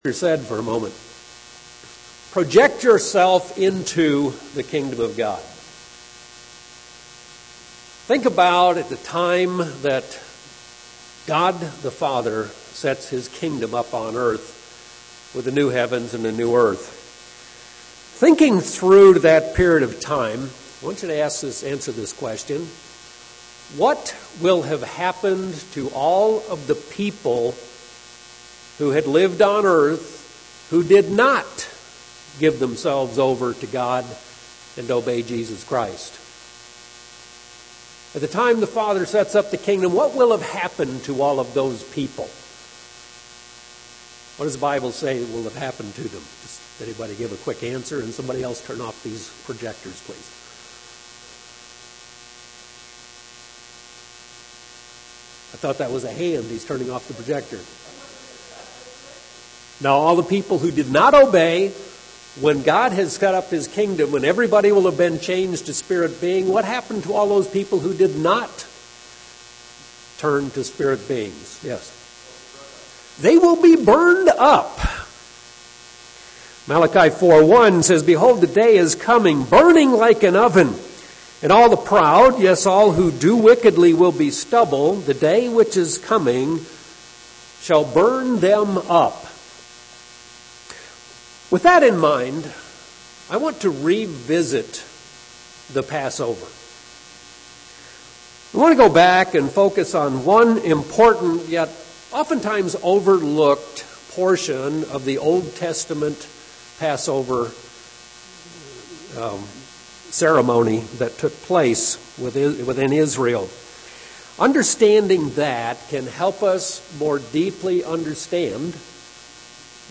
This sermon discusses the significance of the burnt offering in its relation to Passover - and to us and our relationship with God.
Given in Phoenix East, AZ